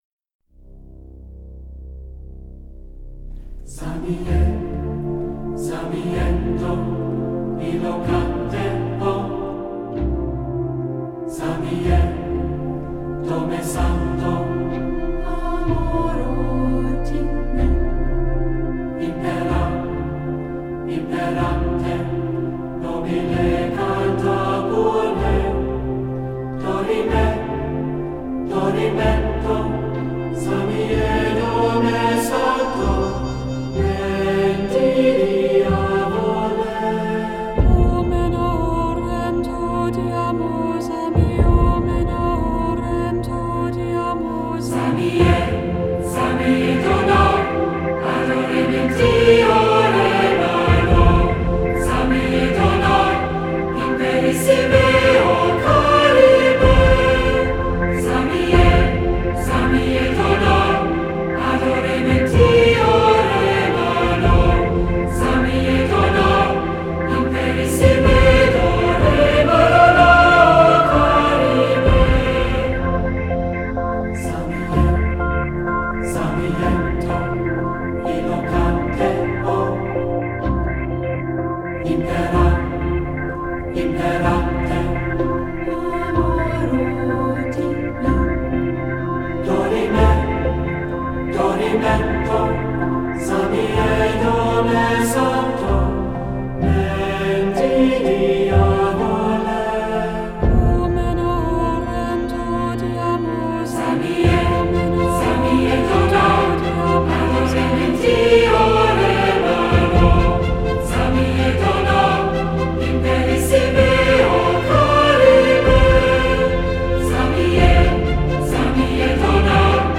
这里的曲子音质都还不错